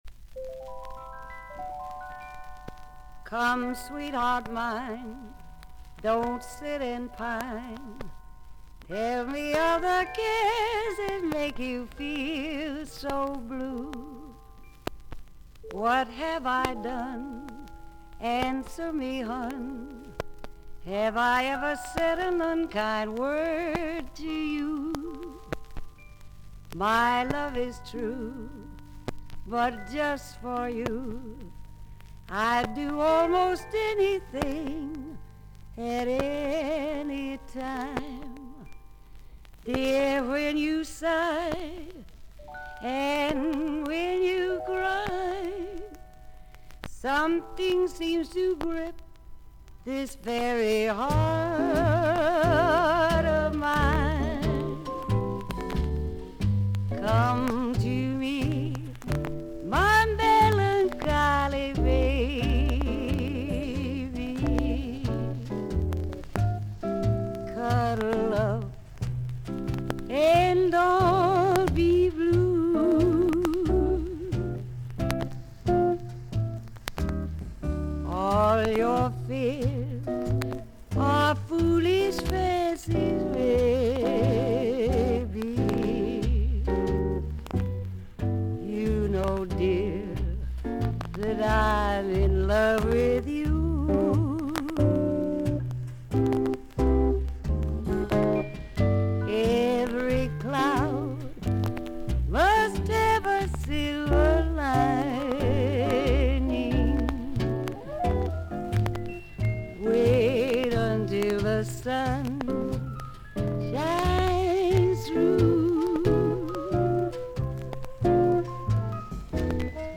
A5に深い引っかきキズあり、大きめの周回ノイズあり。
少々サーフィス・ノイズあり。
30年代から活躍する麗しい女性シンガー。